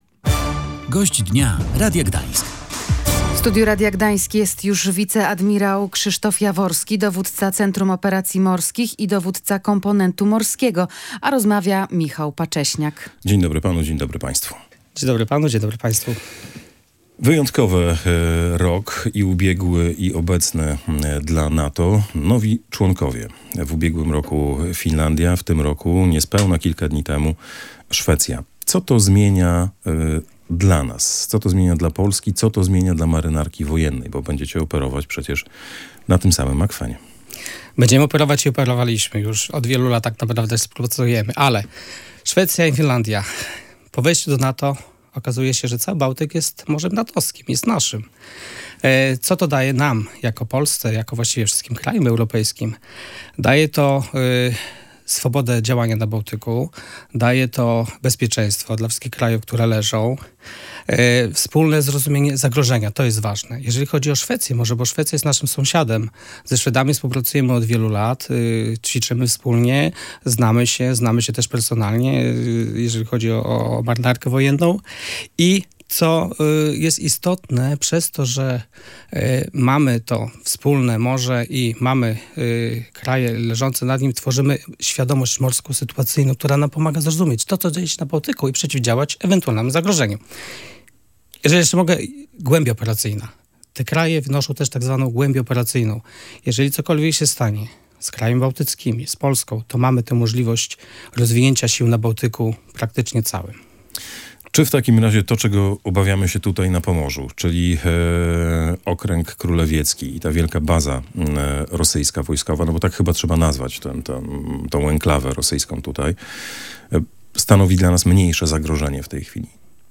Zmieniliśmy też mentalność – mówił w Radiu Gdańsk wiceadmirał Krzysztof Jaworski, dowó